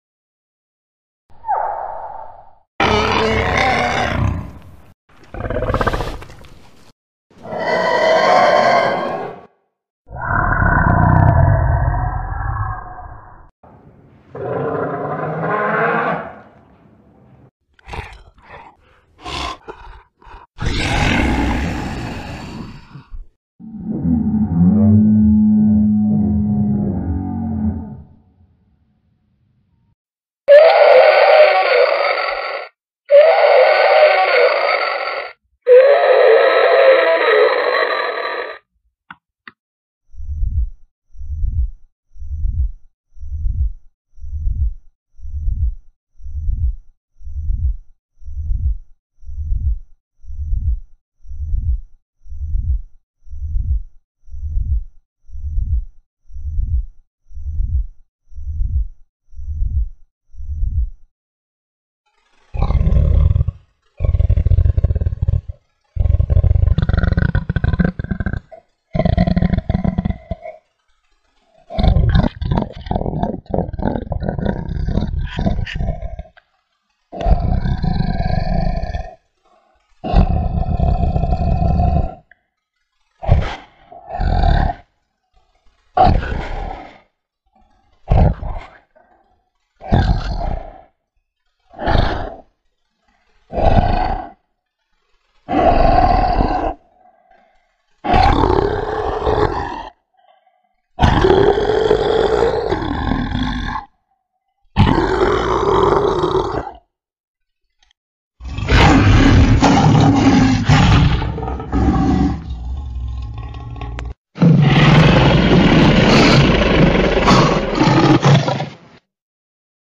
دانلود آهنگ دایناسور 2 از افکت صوتی انسان و موجودات زنده
دانلود صدای دایناسور 2 از ساعد نیوز با لینک مستقیم و کیفیت بالا
جلوه های صوتی